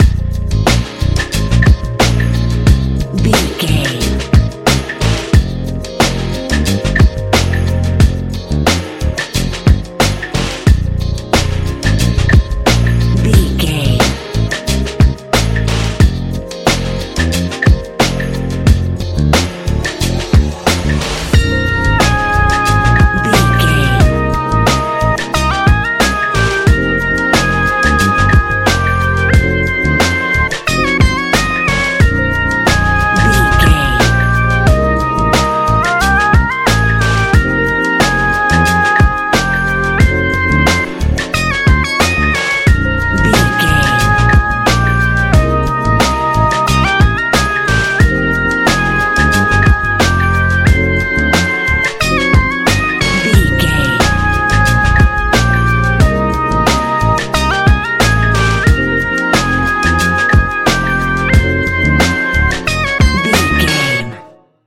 Ionian/Major
A♯
chilled
laid back
Lounge
sparse
new age
chilled electronica
ambient
atmospheric